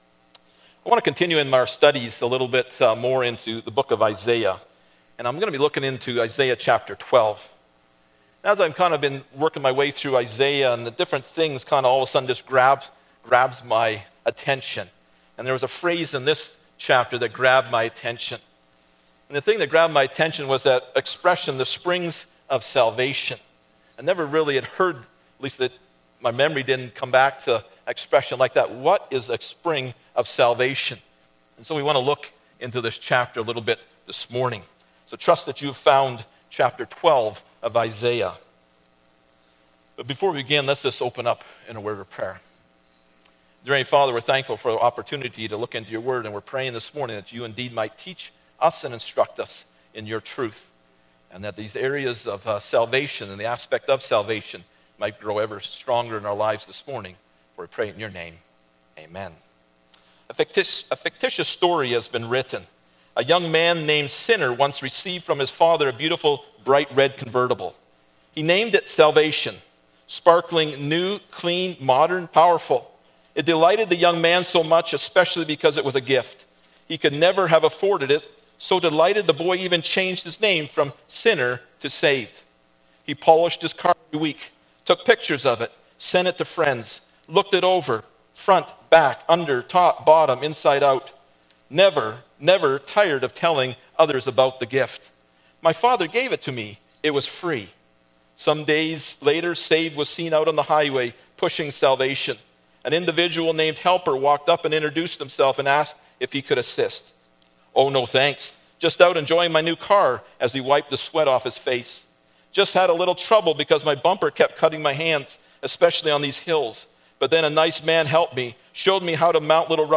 Is 12:1-6 Service Type: Sunday Morning Bible Text